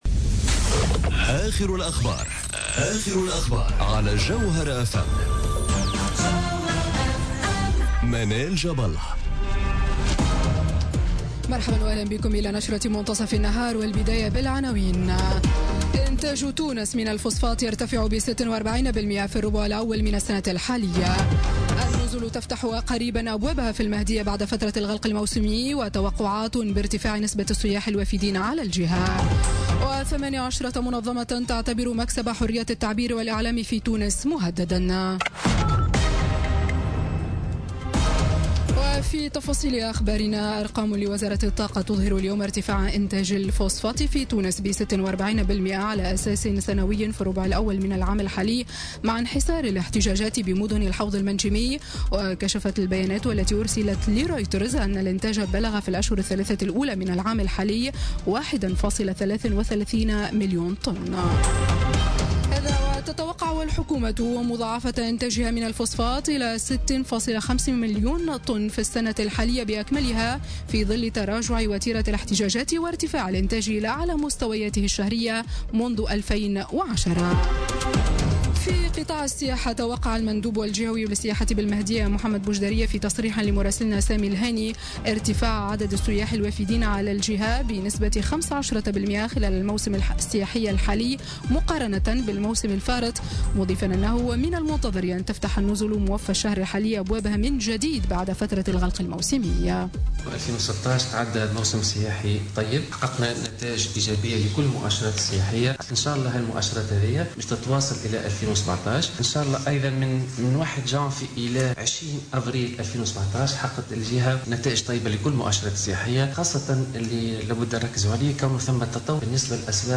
نشرة أخبار منتصف النهار ليوم الثلاثاء 2 ماي 2017